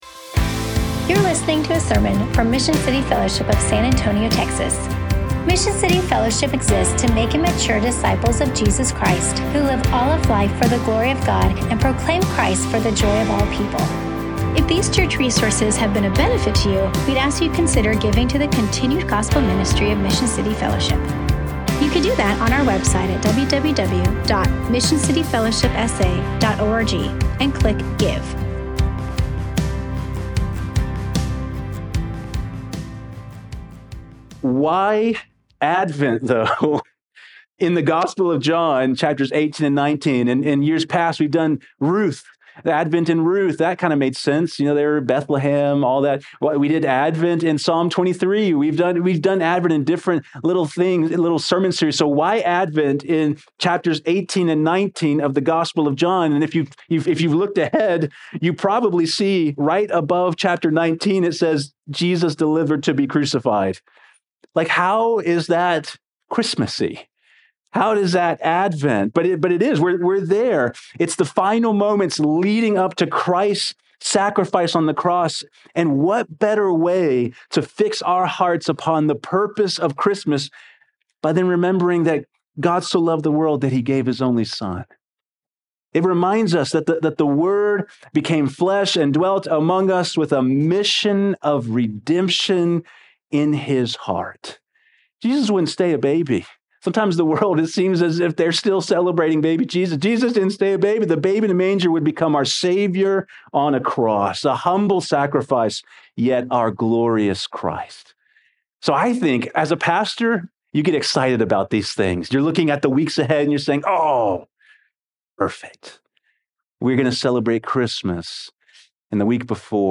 Unfortunately, this Sunday’s scripture reading was not recorded before the sermon and picked up in the middle of the sermon intro. The sermon is from John 18:1-12.